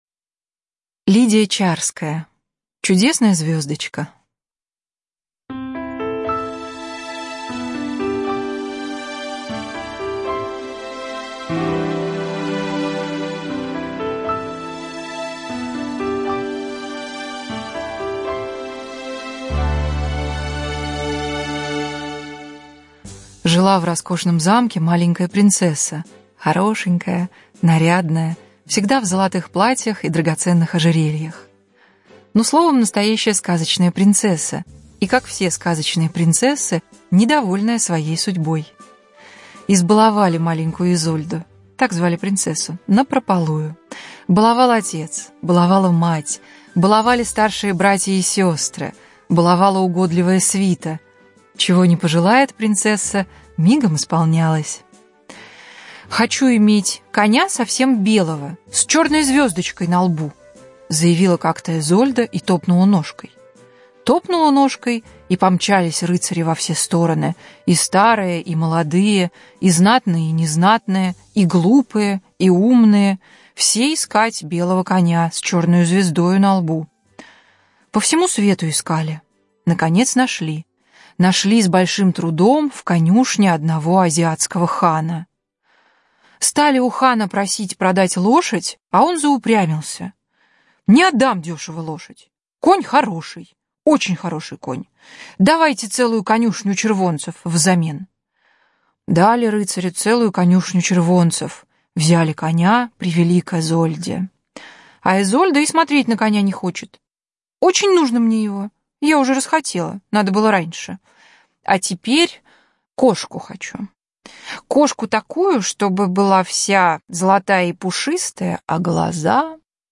Чудесная звездочка - аудиосказка Чарской - слушать онлайн